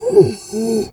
bear_pain_whimper_07.wav